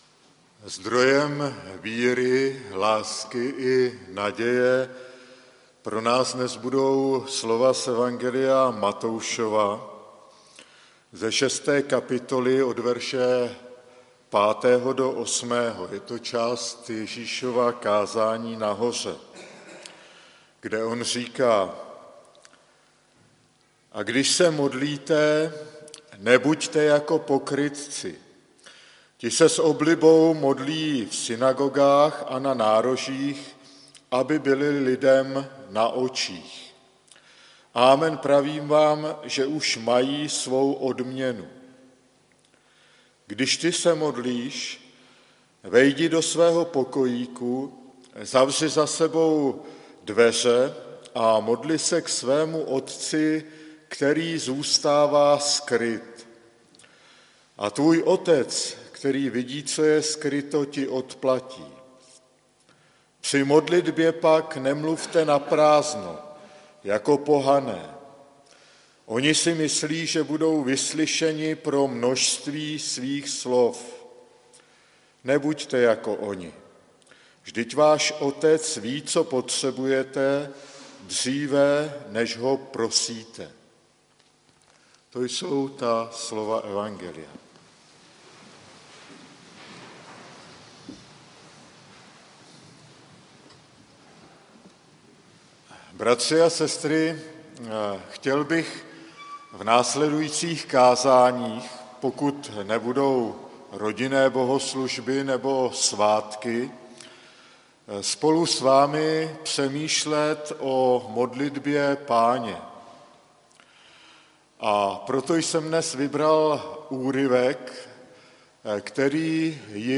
kázání - "Otče náš I."